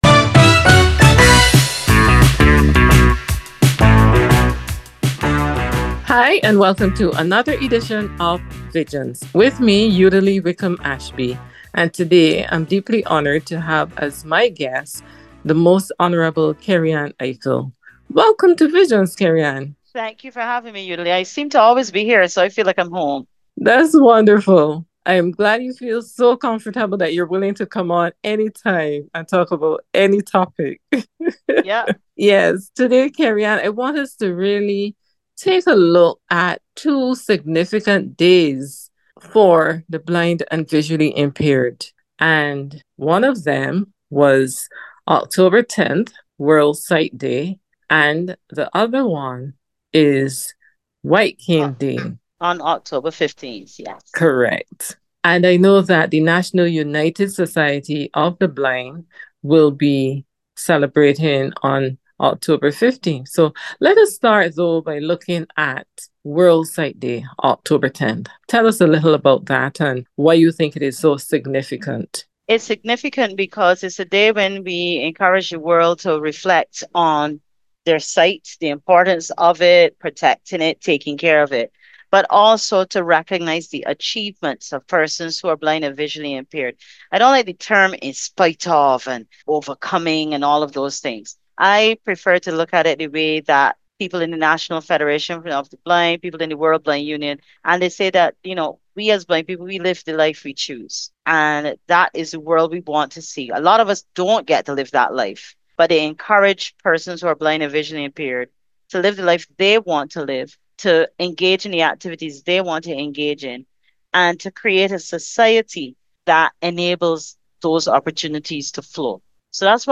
Interview with The Most Honourable Kerryann Ifill